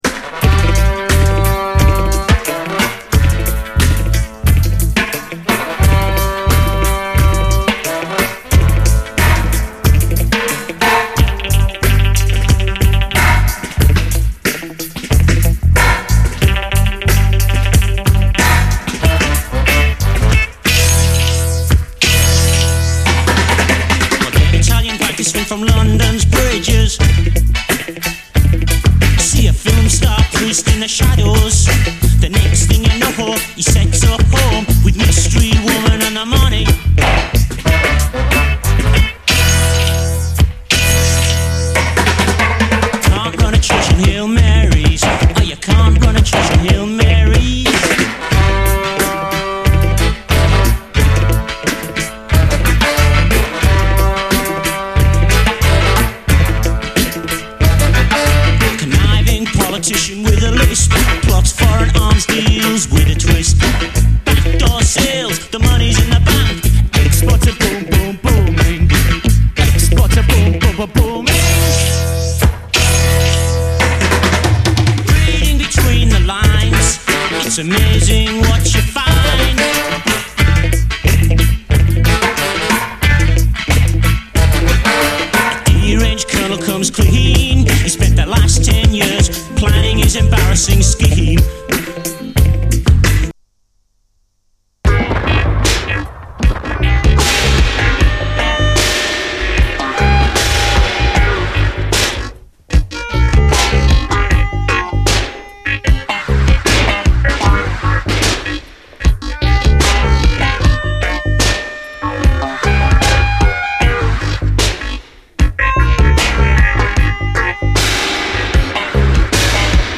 80's～ ROCK, REGGAE, ROCK
ニューウェーヴ〜パンク経由の都市型硬質アヴァンギャルド・レゲエ〜ダブ！ファンキー・パンキー・レゲエ
メロディカ入りインダストリアル・ダブ